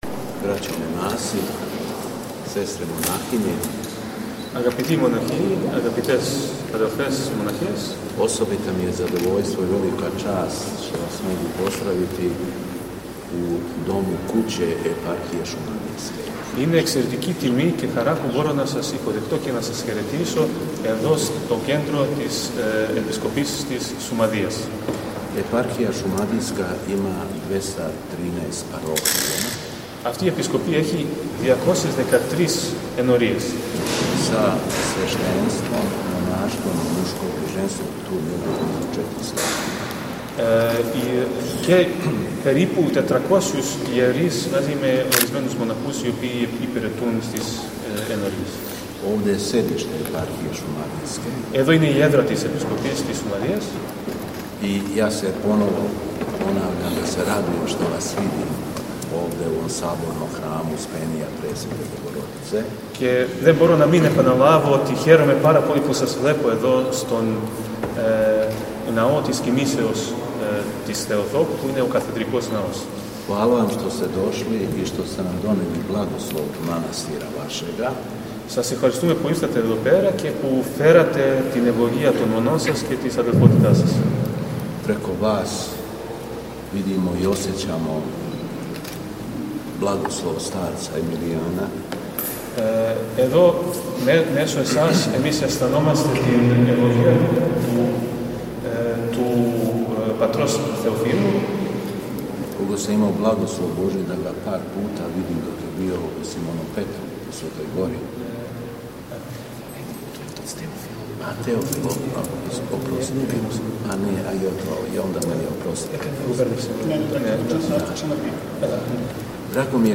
Беседа Његовог Преосвештенства Епископа шумадијског г. Јована
Посетивши Саборни храм у Крагујевцу, Епископ Јован је бираним речима поздравио госте: